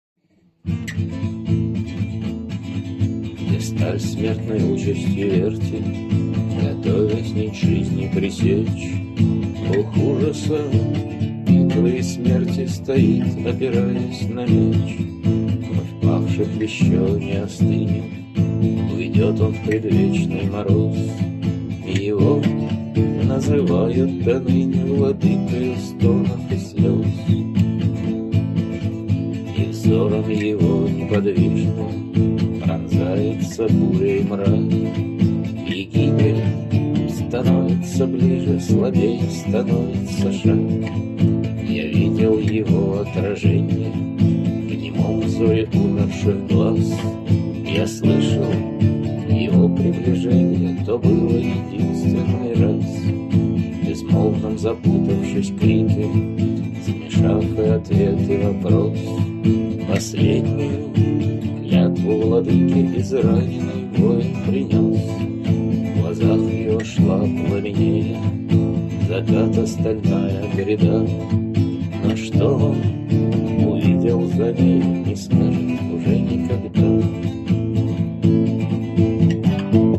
temnodar.mp3 (1250k) Вторая ария Темнодара